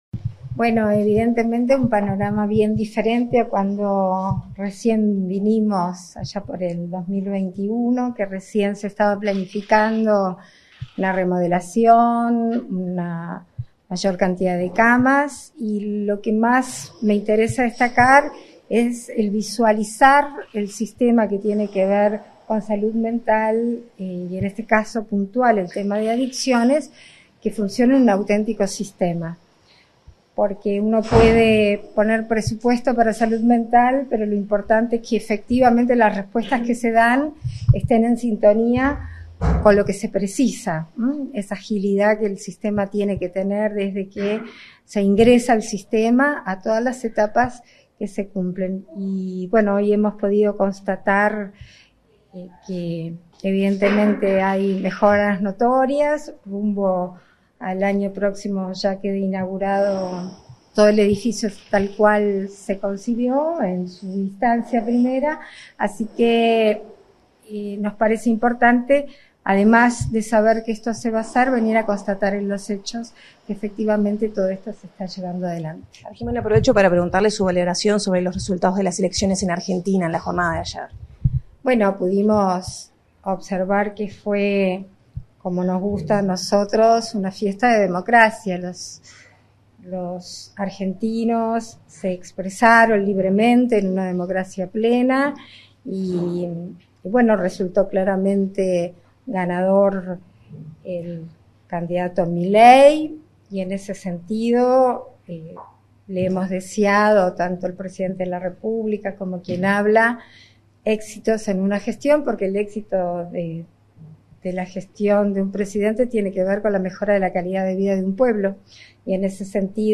Declaraciones de la presidenta de la República en ejercicio, Beatriz Argimón
Tras su visita al Centro Nacional de Información de Red de Drogas Portal Amarillo, la presidenta de la República en ejercicio, Beatriz Argimón,